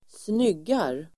Uttal: [²sn'yg:ar]